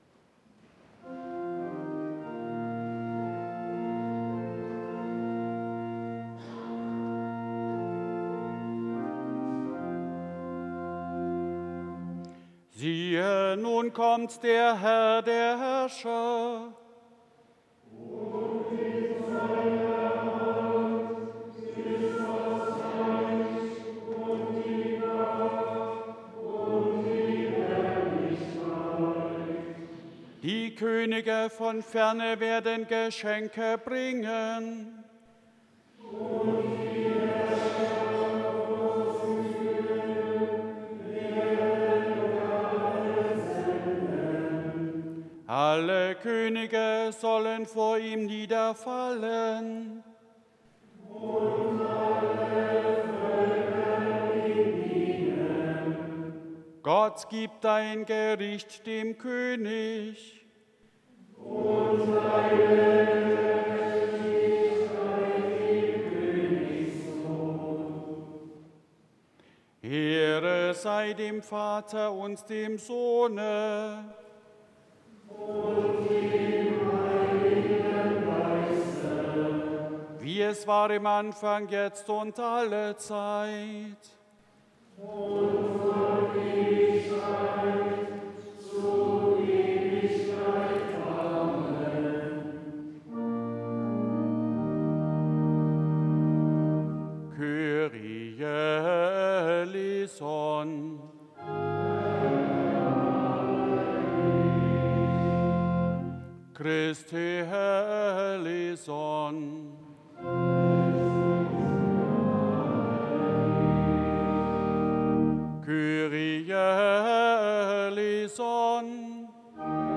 Eingangsliturgie Ev.-Luth.
Audiomitschnitt unseres Gottesdienstes zum Epipaniasfest 2026.